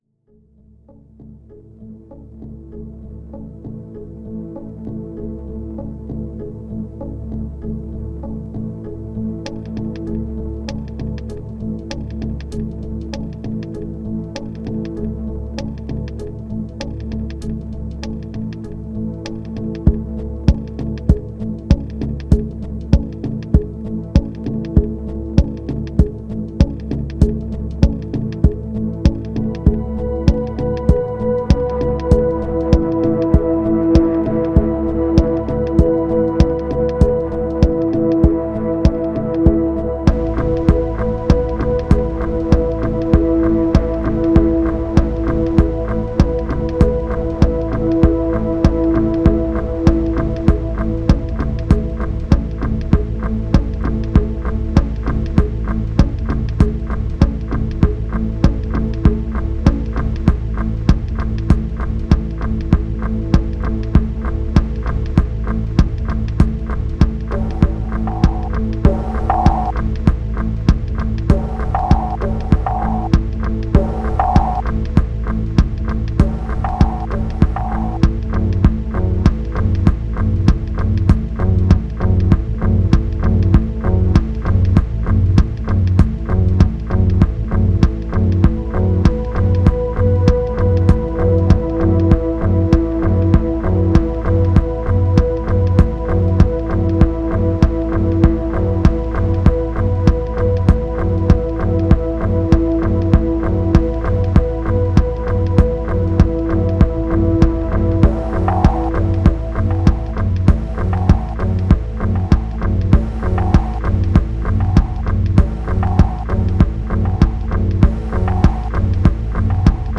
Electro House Mono Kicks Eq Generic Moods Polar Schema